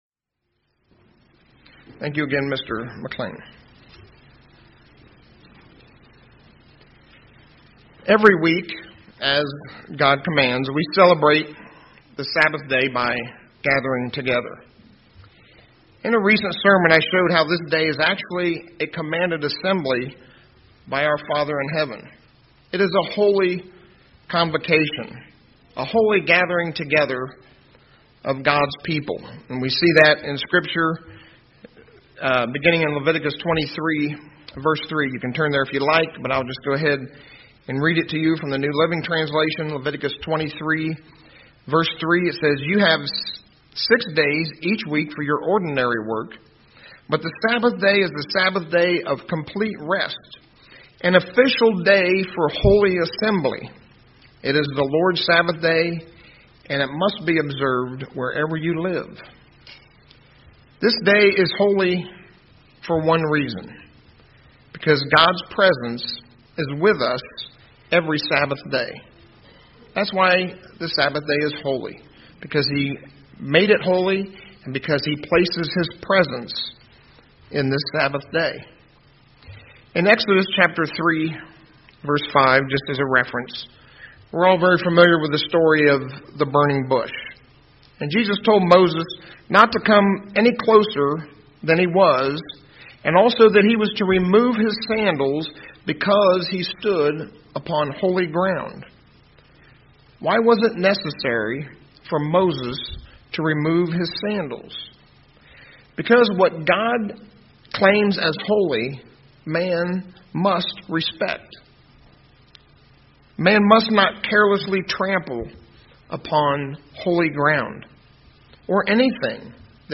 Sabbath Notes Exo. 3:5 1.